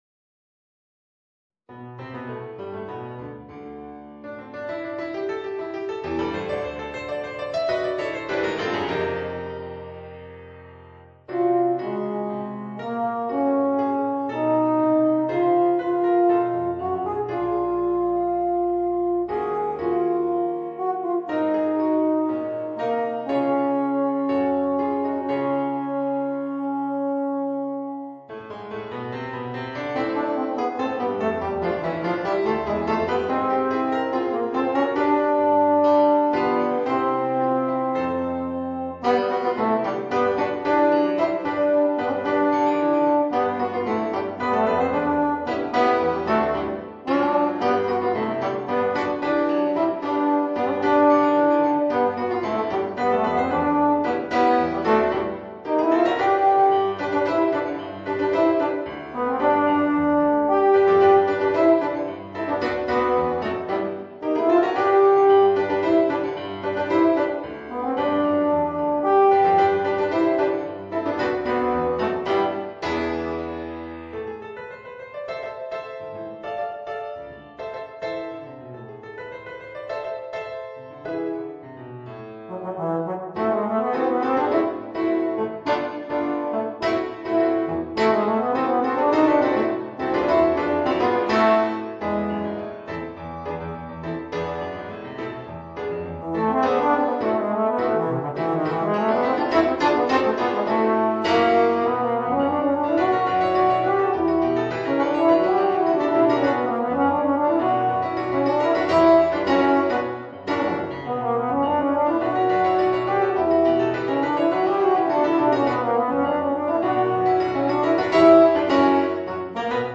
Voicing: Euphonium and Piano